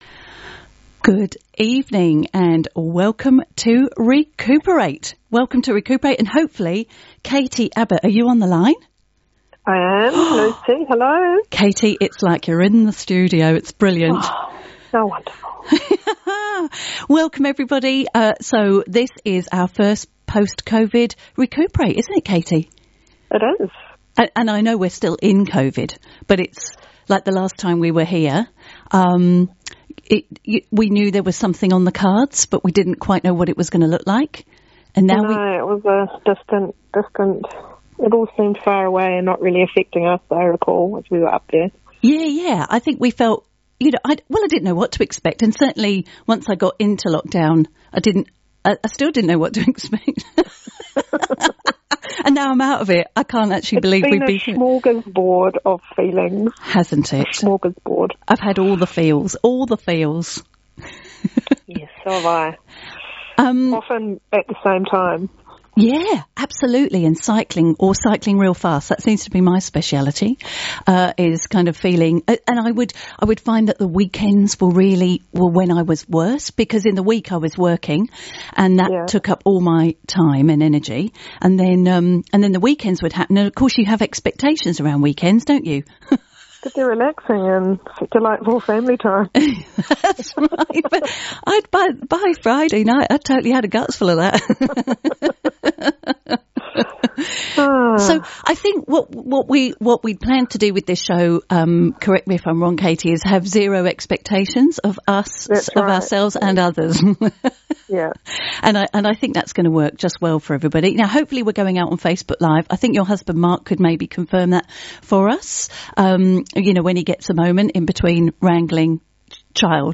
May’s episode of ReCooper8 is available now (apologies for the tardiness – it was first aired on Arrow FM at the end of May!).